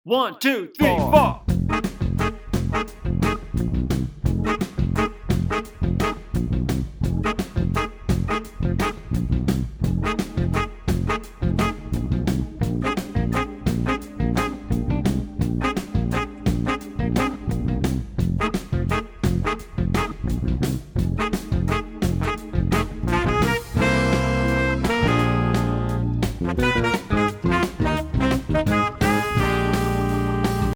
Voicing: Alto Saxophone